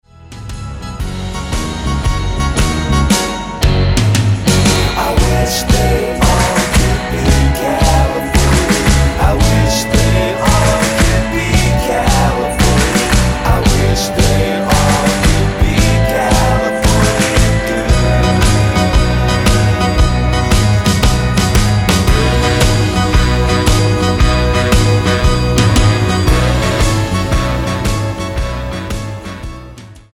--> MP3 Demo abspielen...
Tonart:B mit Chor